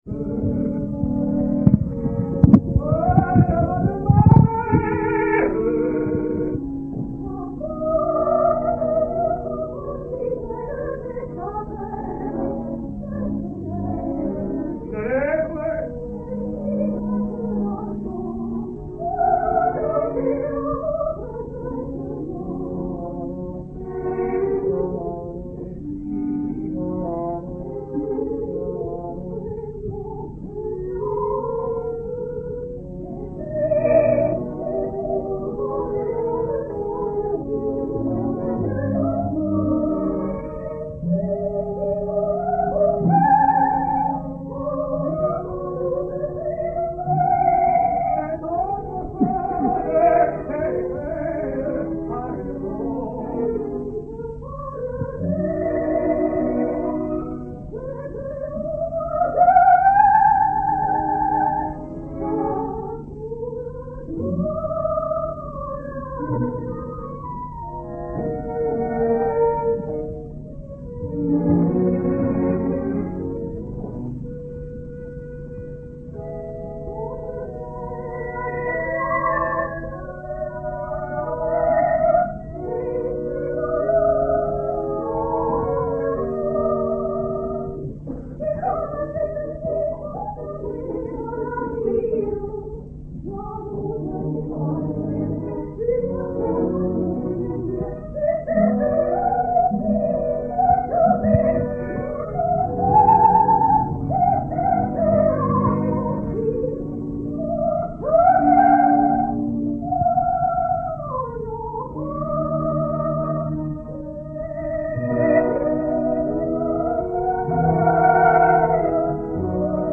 Aix-en-Provence, 19 January 1964